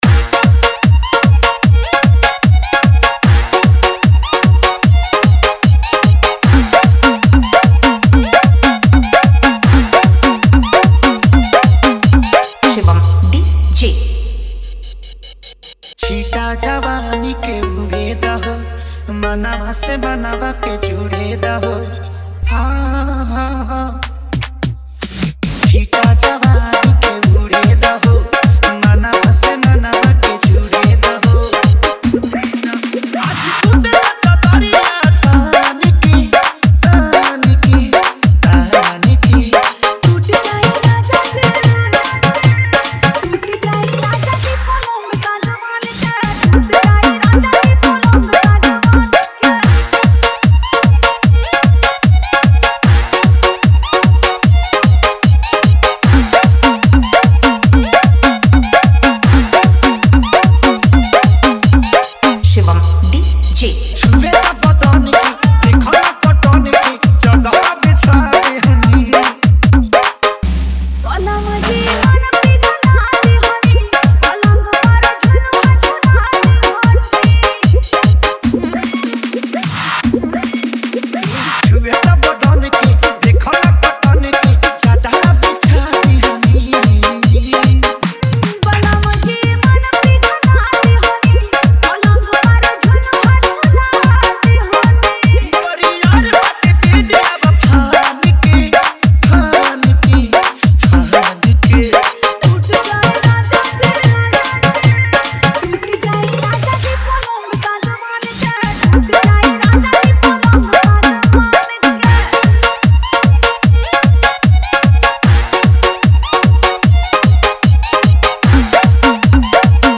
2025 Bhojpuri DJ Remix - Mp3 Songs